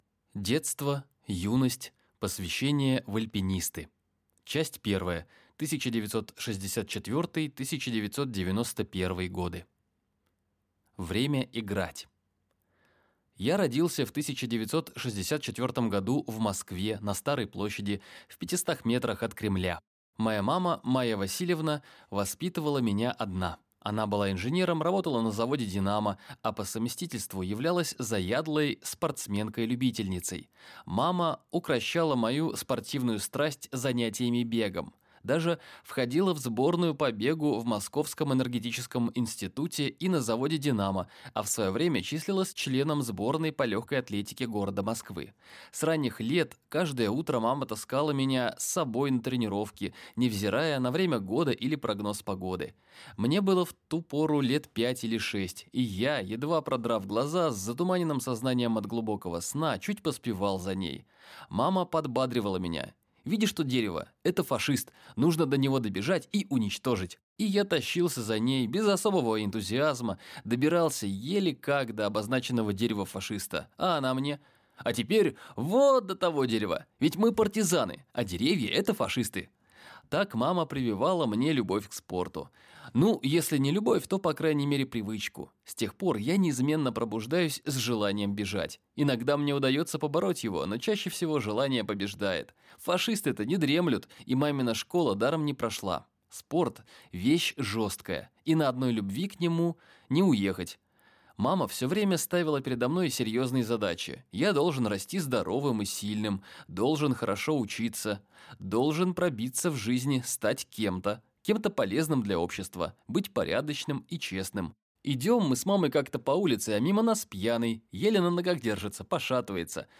Аудиокнига Ген высоты. Откровенная история десятикратного восходителя на Эверест | Библиотека аудиокниг